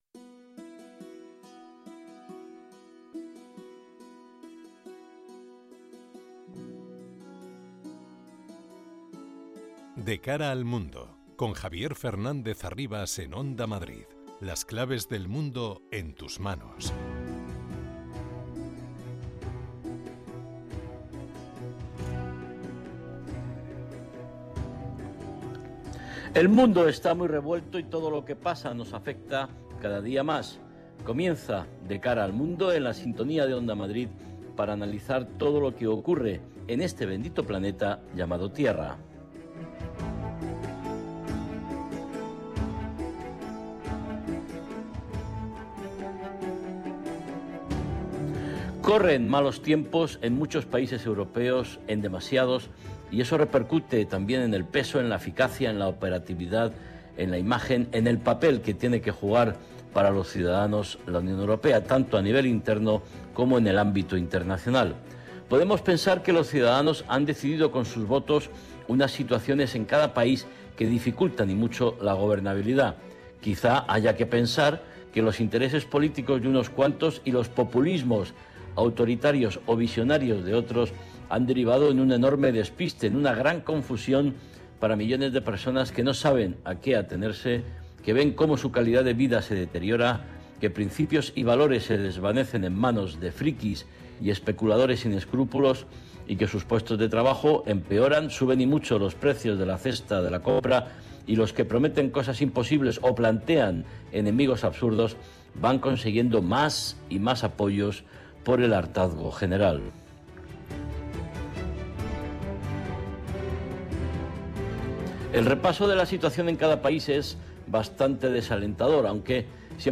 entrevistas a expertos y un panel completo de analistas